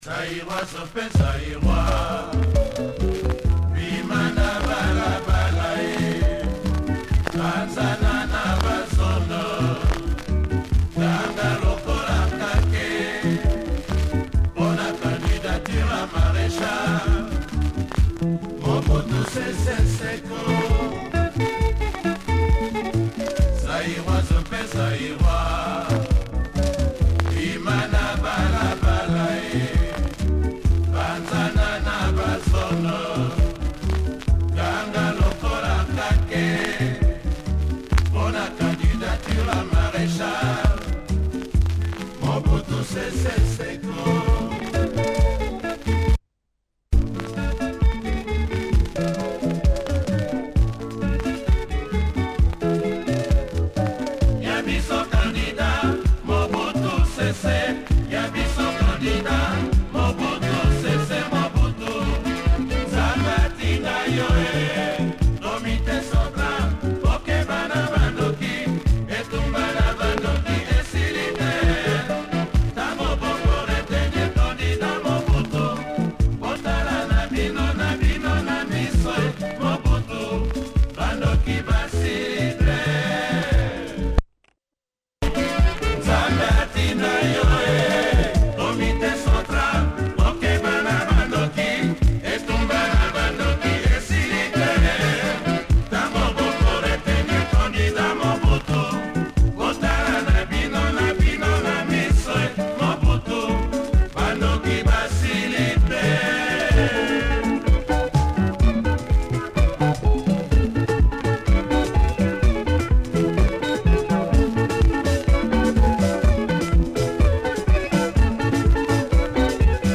plays with some noise